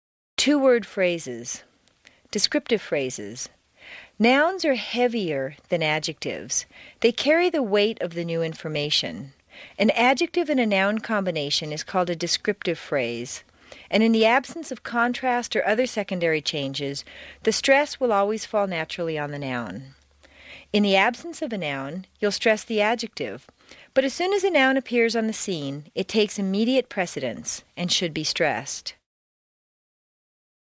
在线英语听力室美式英语正音训练第45期:Two-Word Phrases的听力文件下载,详细解析美式语音语调，讲解美式发音的阶梯性语调训练方法，全方位了解美式发音的技巧与方法，练就一口纯正的美式发音！